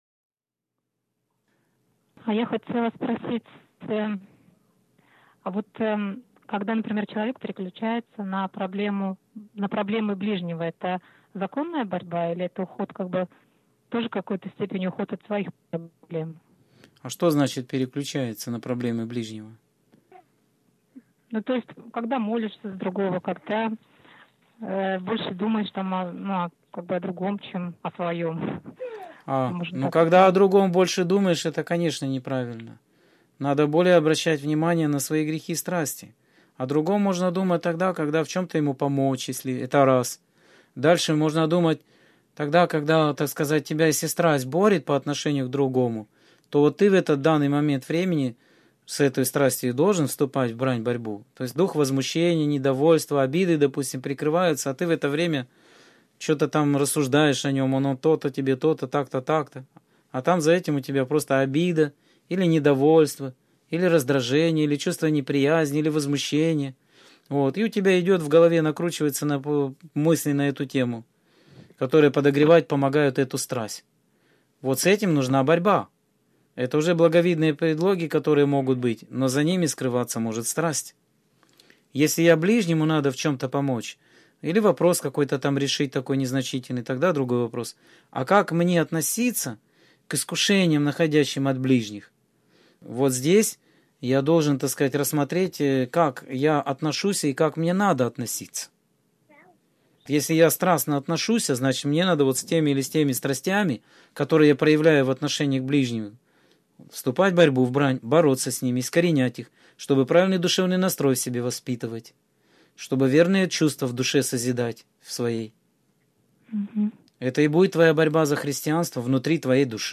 Скайп-беседа 23.02.2013 — ХРИСТИАНСКАЯ ЦЕРКОВЬ